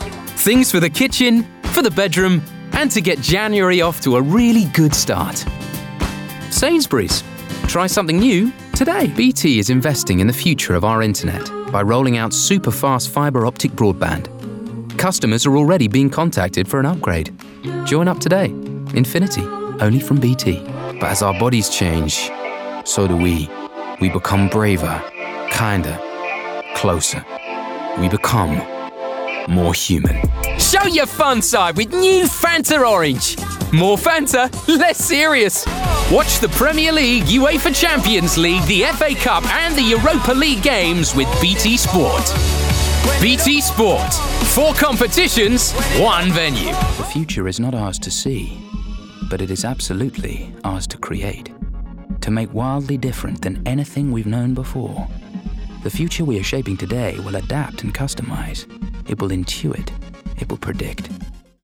RP ('Received Pronunciation')
Commercial, Promo, Doco, Showreel